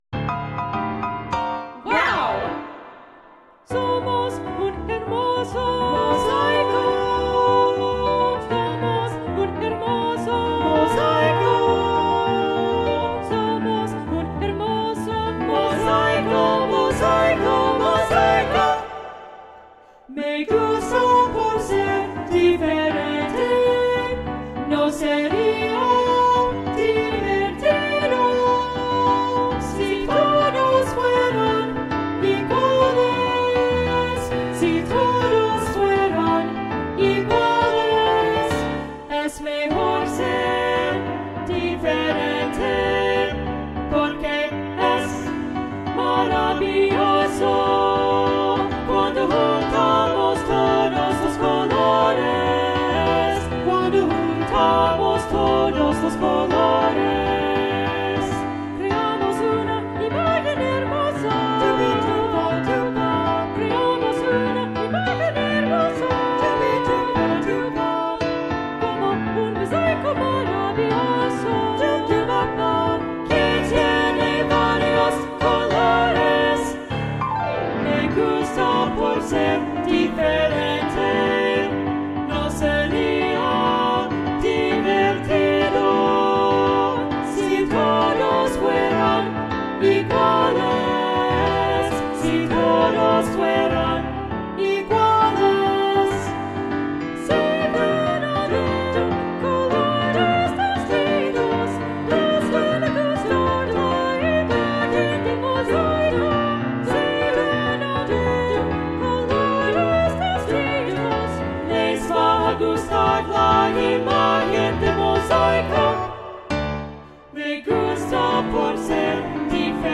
Voicing: SSA (opt. alto solo)
Instrumentation: piano